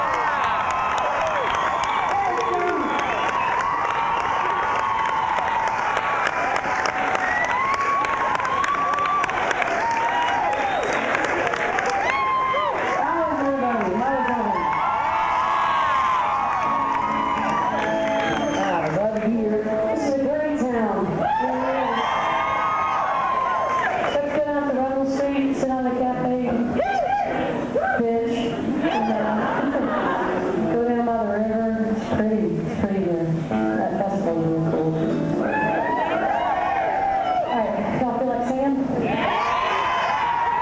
04. crowd (0:40)